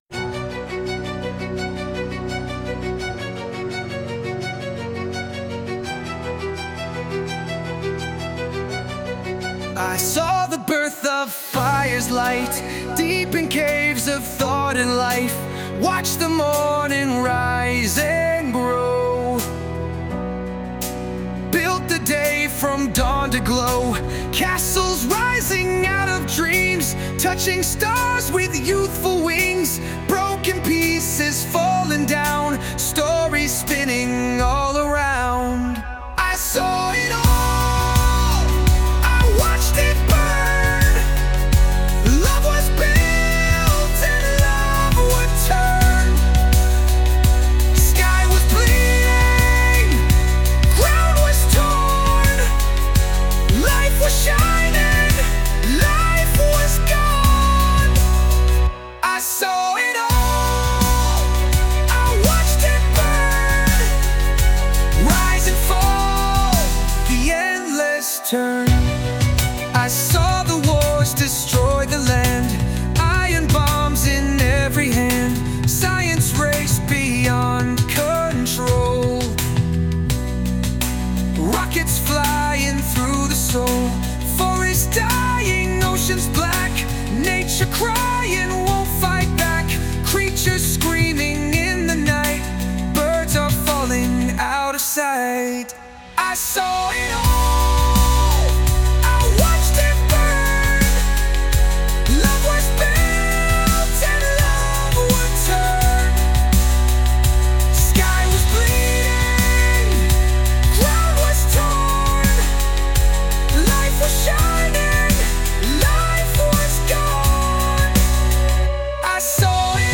English, Romantic, Funk, Trance, Rock | 13.04.2025 19:20